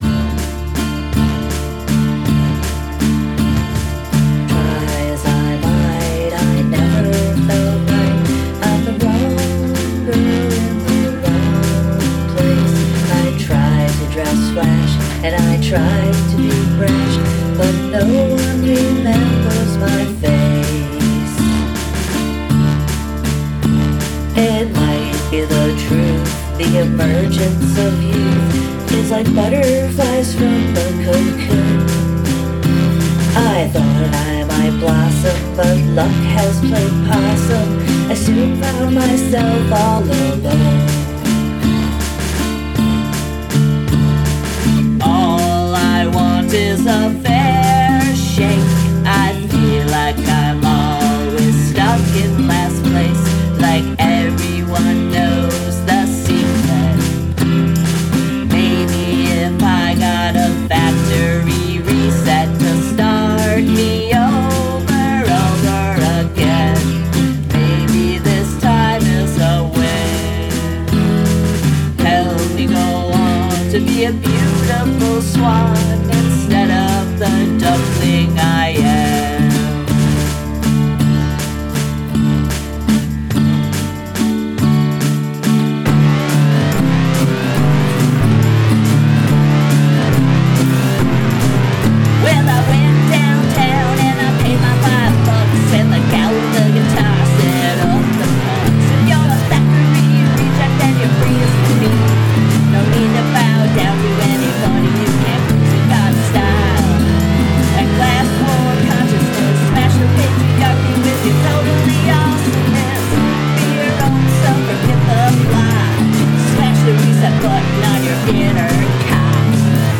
Must include at least one mood shift (musical & lyrical)